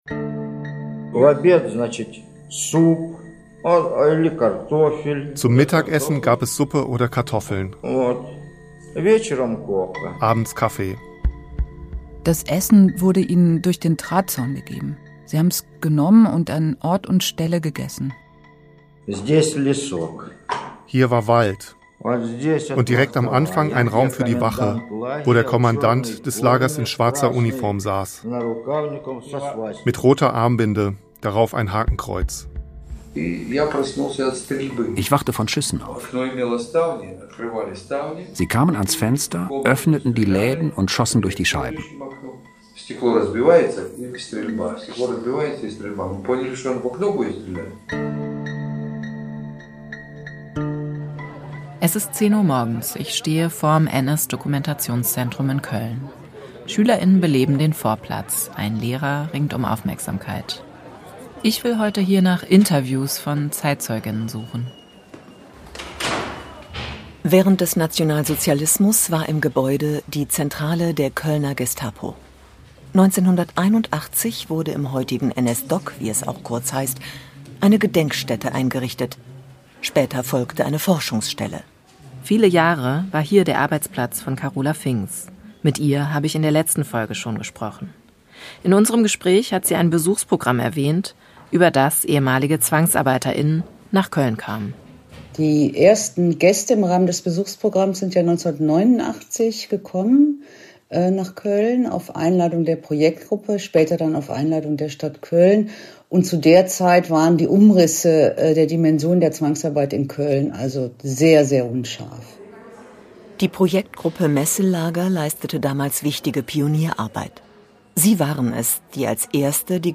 Sie alle waren aus unterschiedlichen Gründen im Gremberger Wäldchen und mussten dort unter schrecklichen Bedingungen leben. In Interviews haben sie davon erzählt.